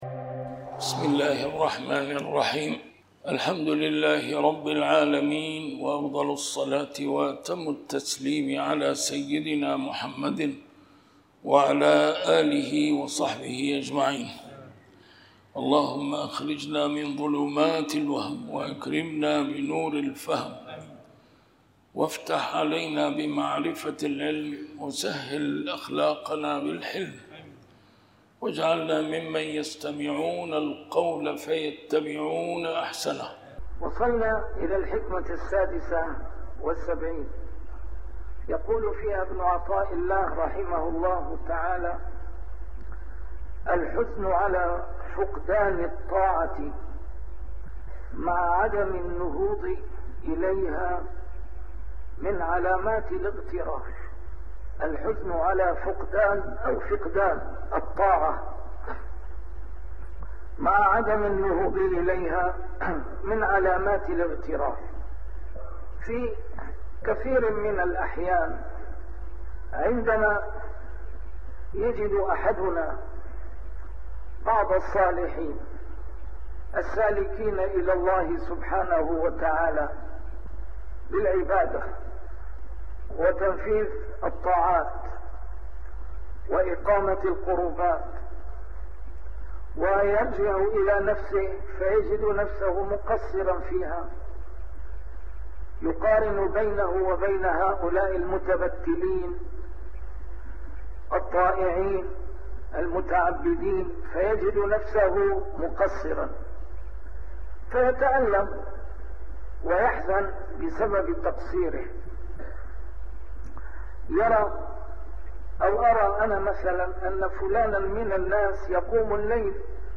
A MARTYR SCHOLAR: IMAM MUHAMMAD SAEED RAMADAN AL-BOUTI - الدروس العلمية - شرح الحكم العطائية - الدرس رقم 98 شرح الحكمة 76